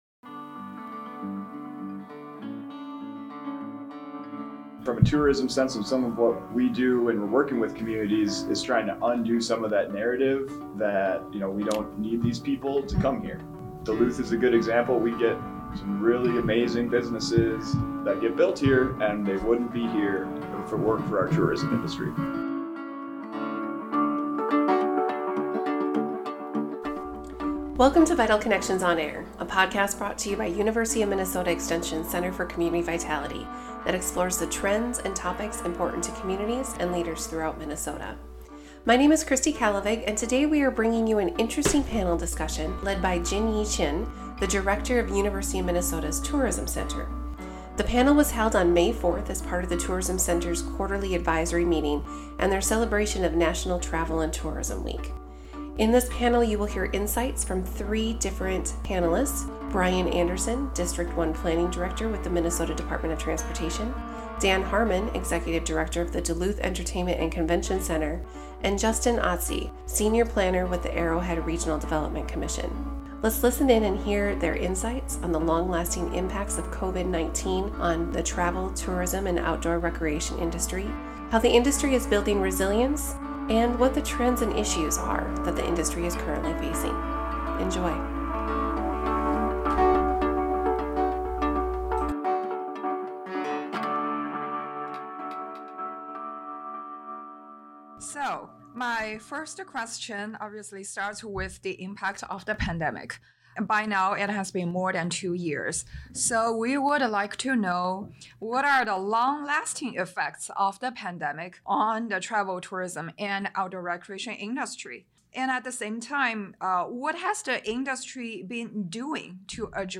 While the impact of COVID-19 will linger for years to come, businesses and community organizations across Minnesota have proven resilient in the face of fluctuating demand, workforce shortages, climate changes, and other issues. During this conversation, our panelists discuss examples of how they have responded to these challenges and much more.